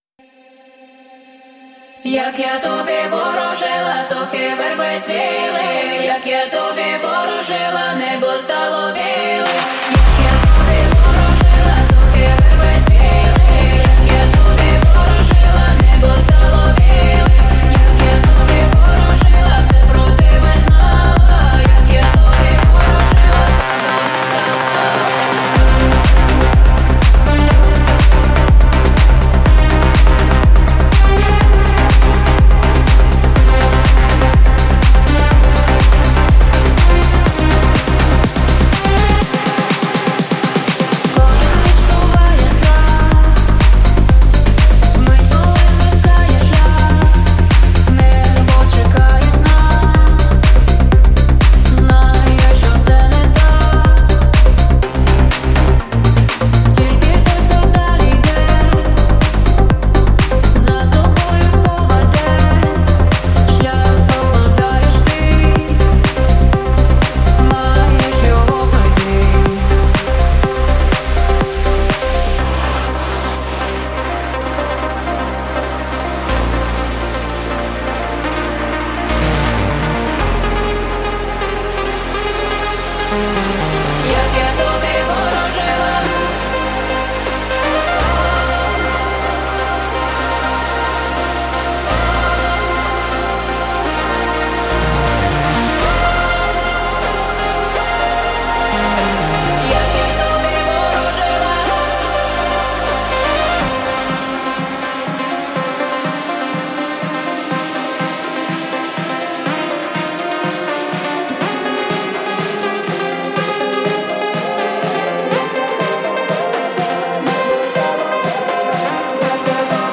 Show of the best techno DJ of Ukraine